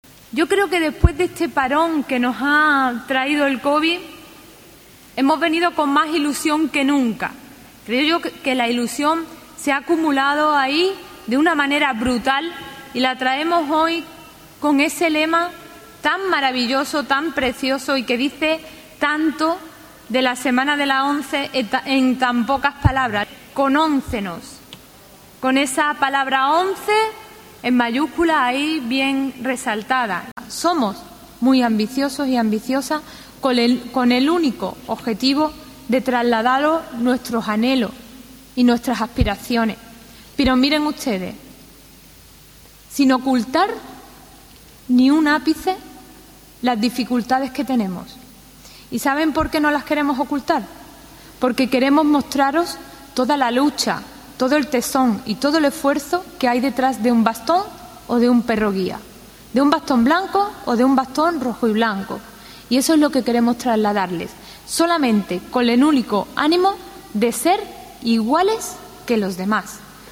El presidente de la Junta de Andalucía inaugura la Semana ONCE ‘ConONCEnos’ y anuncia una revolución en la atención social - PortalONCE